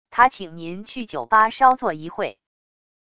他请您去酒吧稍作一会wav音频生成系统WAV Audio Player